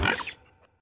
Cri de Gourmelet dans Pokémon Écarlate et Violet.